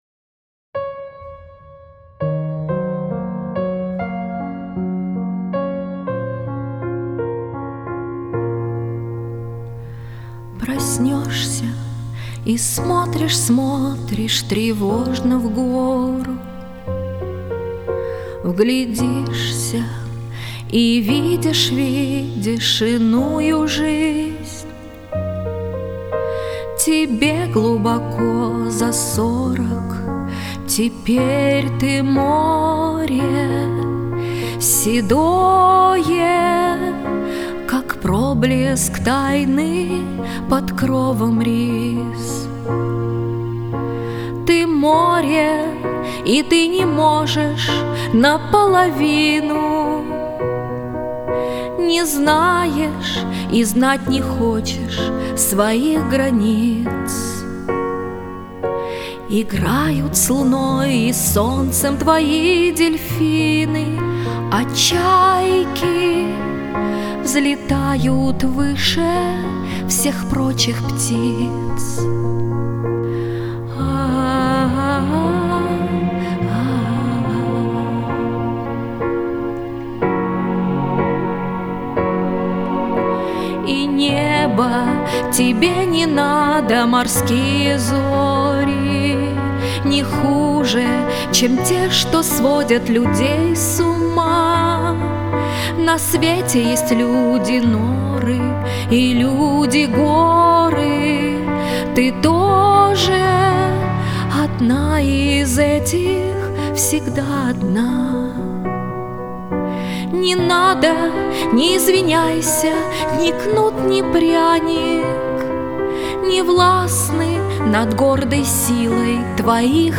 Песни